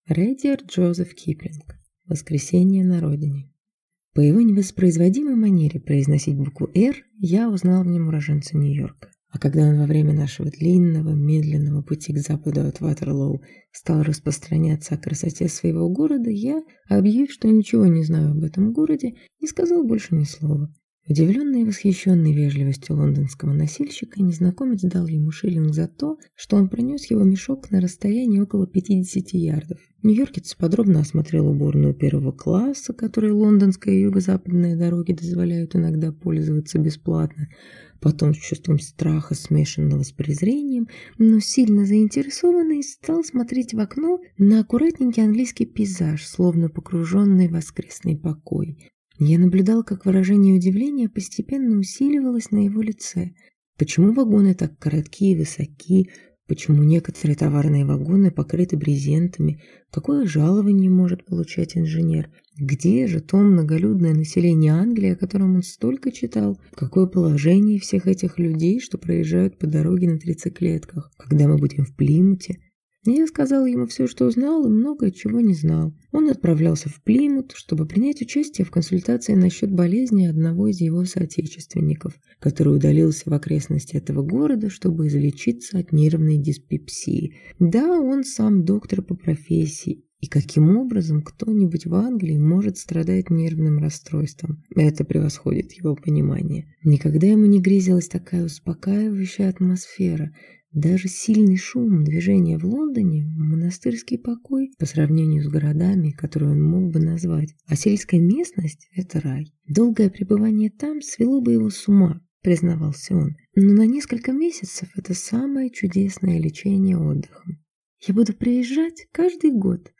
Аудиокнига Воскресение на родине | Библиотека аудиокниг